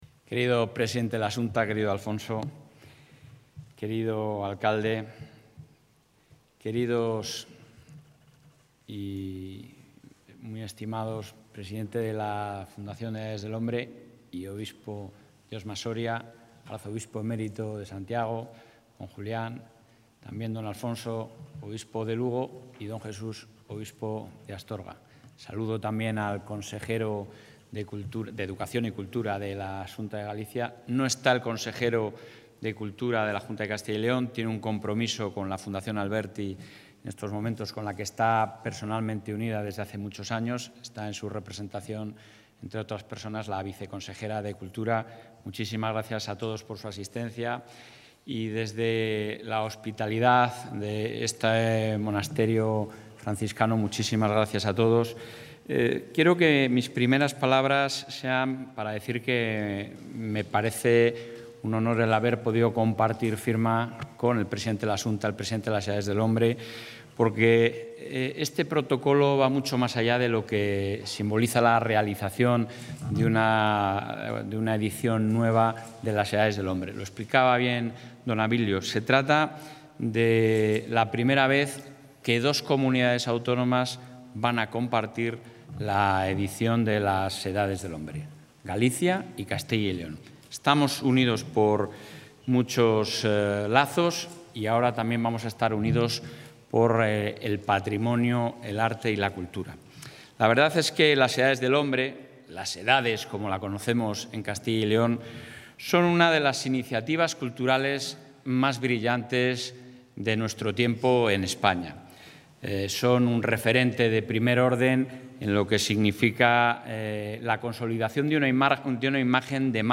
Intervención del presidente de la Junta.
El presidente de la Junta de Castilla y León, Alfonso Fernández Mañueco, y su homólogo en la Xunta de Galicia, Alfonso Rueda, han firmado con la Fundación Edades del Hombre, hoy en O Cebreiro, Lugo, un Protocolo para la XXVII Edición de esta exposición, que tendrá lugar en Villafranca del Bierzo y Santiago de Compostela, entre junio y octubre de 2024.